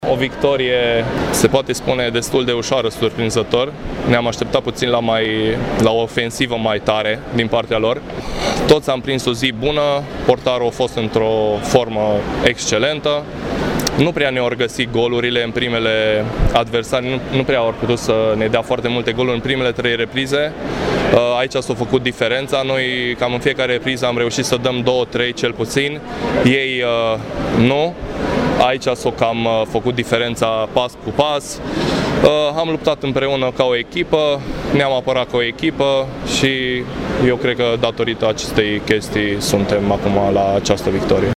Doi dintre jucătorii învingători au vorbit despre reușita din bazinul Ioan Alexandrescu.